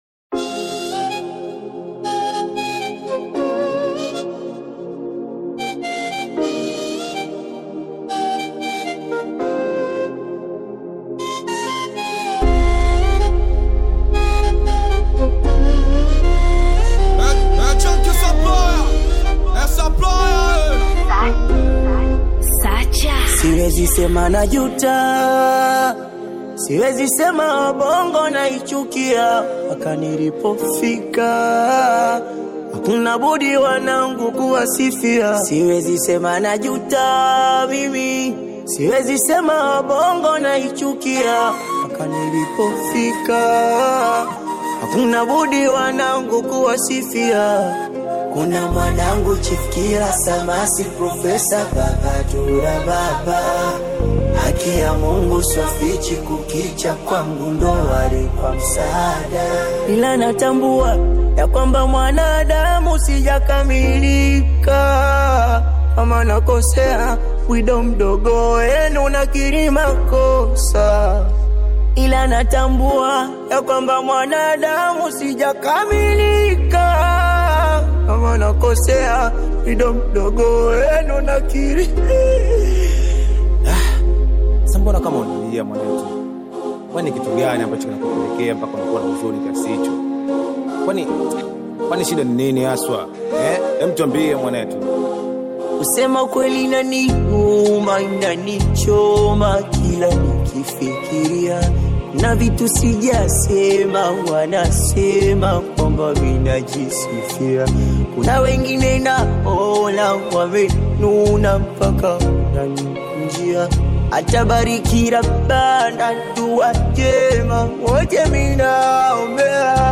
WABONGO | DISS TRACK 👇 👇 👇
SINGELI